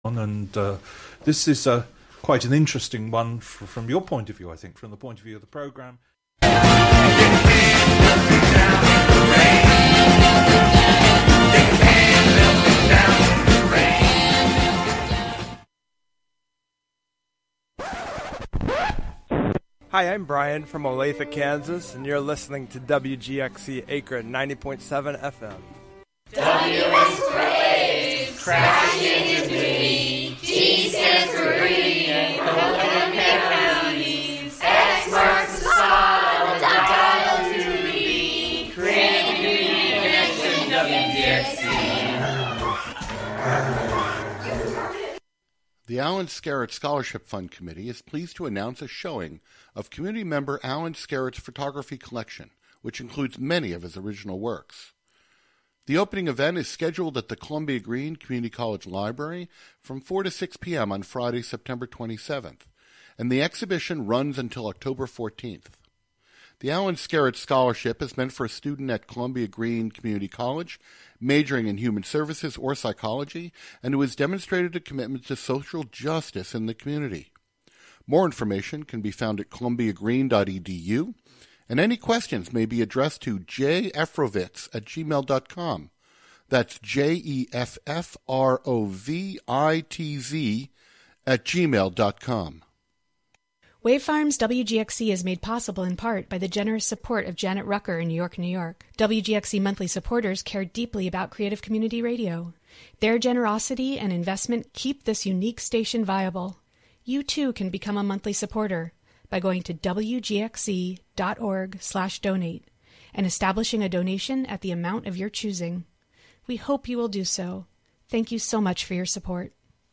Monthly excursions into music, soundscape, audio document, and spoken word, inspired by the wide world of performance. Live from Ulster County.